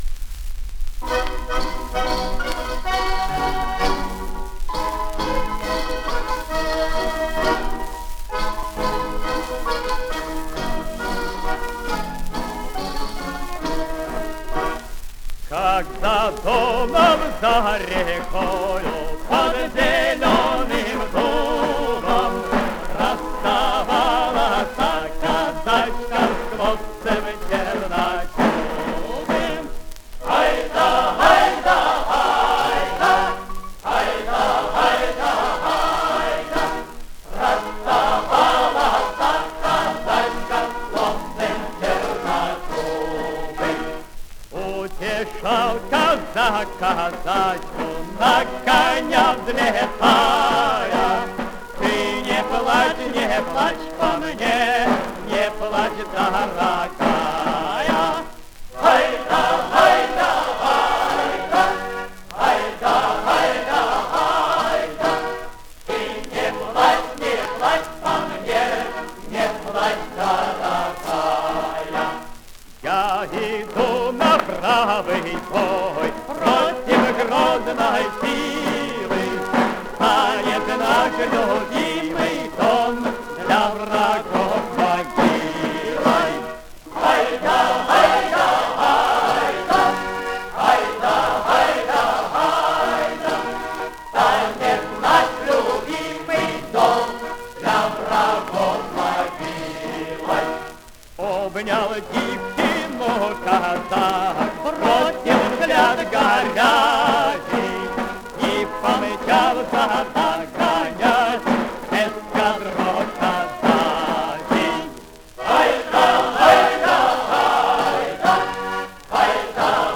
Сокращенный вариант песни.